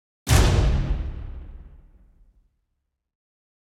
Suspense 1 - Stinger 3.wav